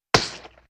splat3.ogg